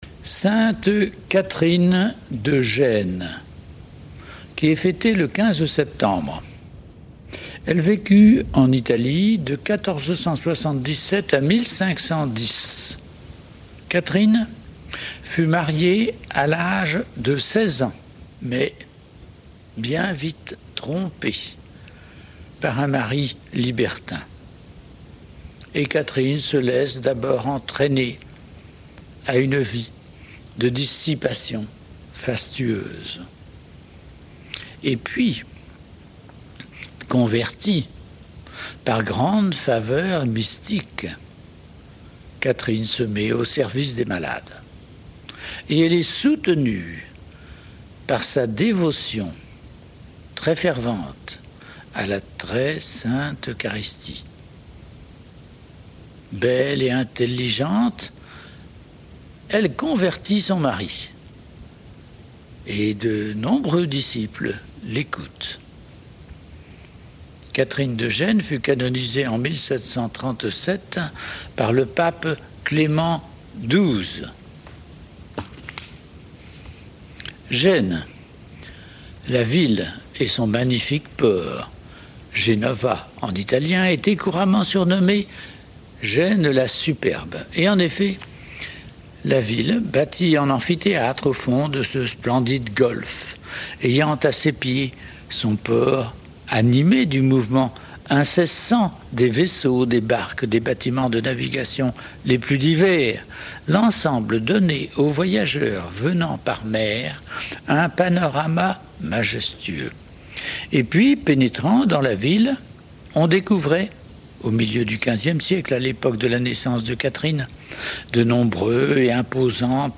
MP3 64Kbps Mono Taille